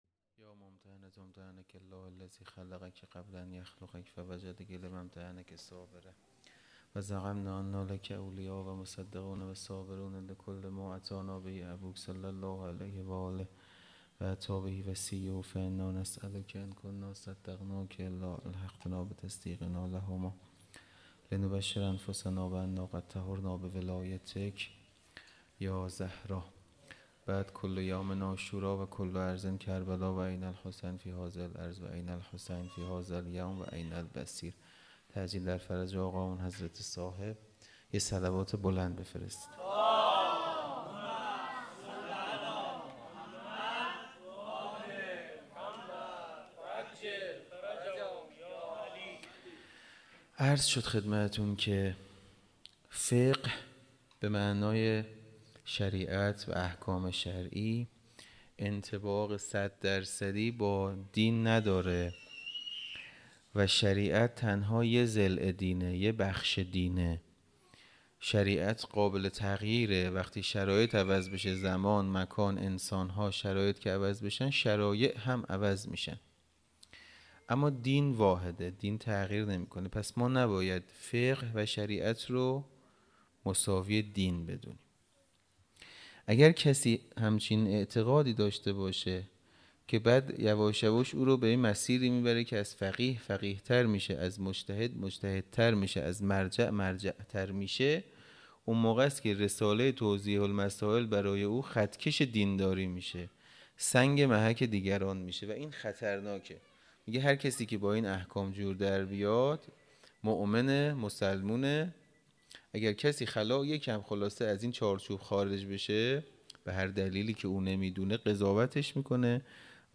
خیمه گاه - حسینیه کربلا - شب 23 ماه رمضان- سخنرانی